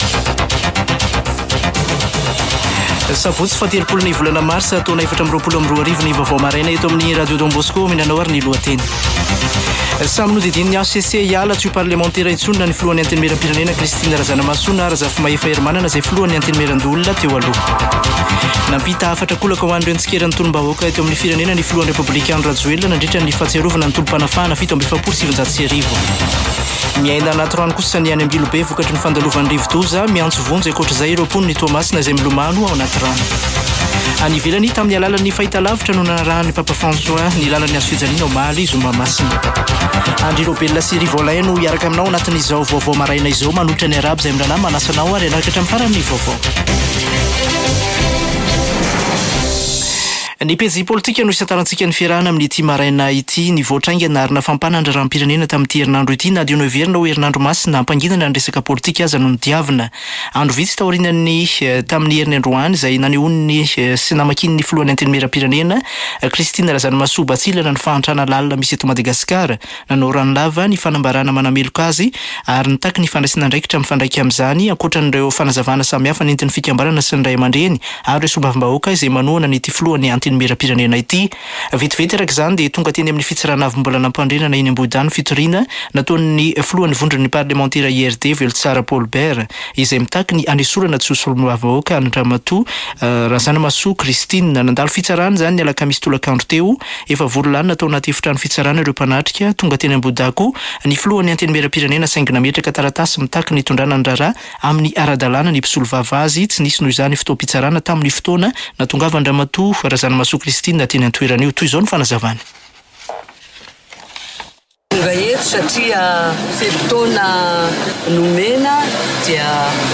[Vaovao maraina] Sabotsy 30 marsa 2024